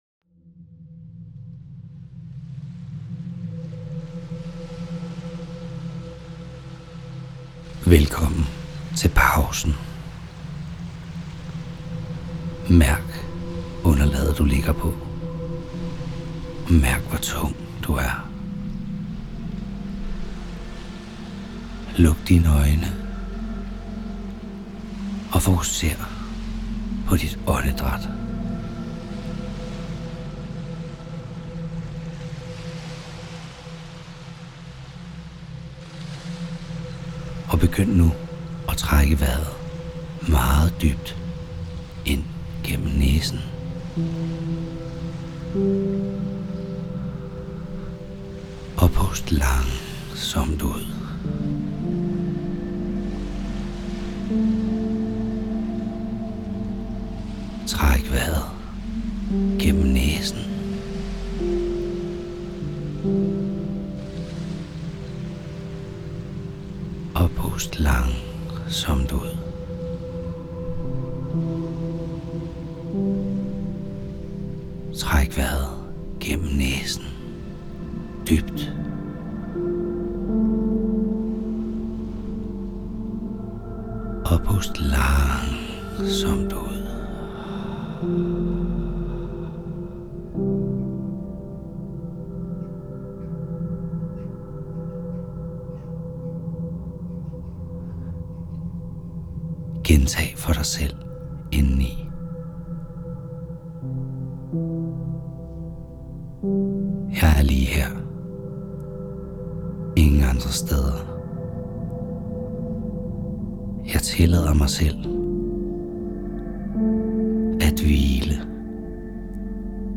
2. Prøv den guidede meditation